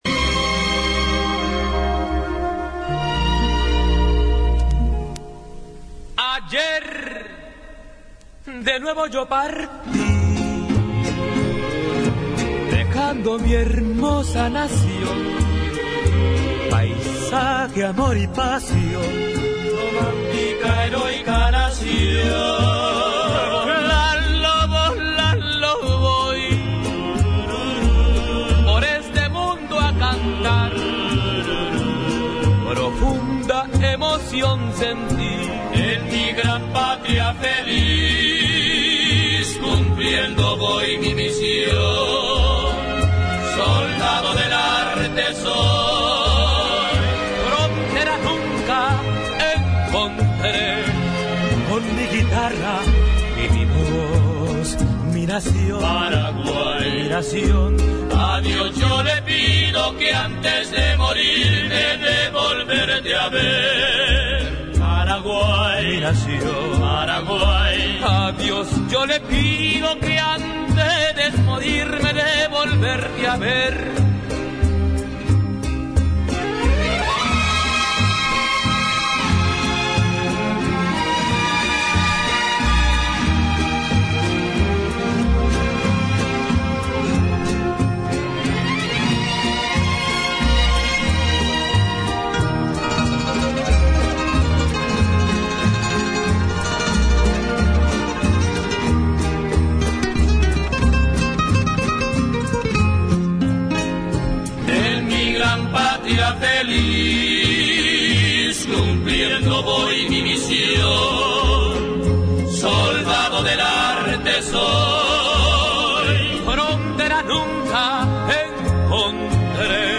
Director del Sistema 911 anuncia en Radio Nacional del Paraguay instalación de cámaras de vigilancia en Calle Palma | RADIO NACIONAL
El programa Palma Segura, se encuentra en marcha, con la instalación de 20 cámaras de vigilancia y el refuerzo de la presencia policial en la zona, resaltó el director del Sistema 911, del Ministerio del Interior, Carlos Escobar, durante su visita a los estudios de Radio Nacional del Paraguay.